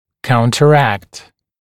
[ˌkauntə’rækt][ˌкаунтэ’рэкт]противодействовать, препятствовать, противостоять